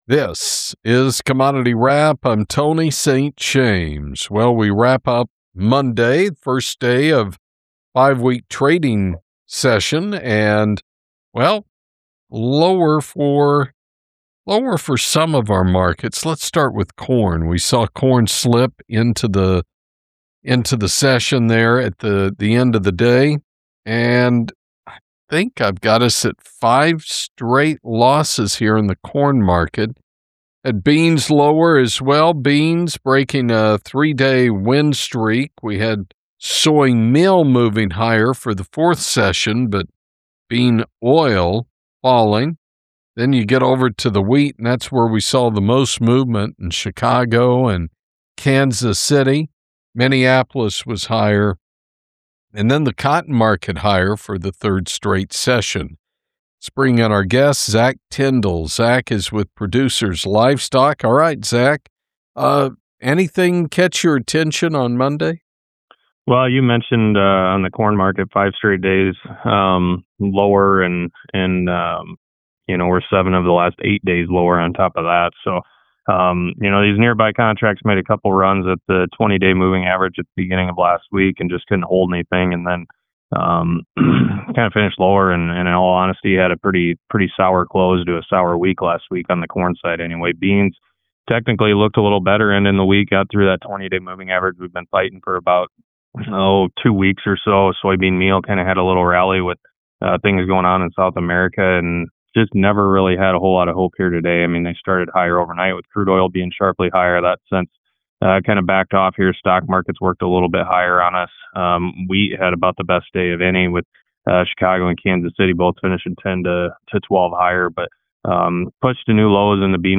Half-hour “preview” of all commodity markets at 8:30am ET immediately preceding the opening of the Chicago Mercantile Exchange. Markets, featuring “live” interviews with brokers in the pit, as well as respected analysts from around the country.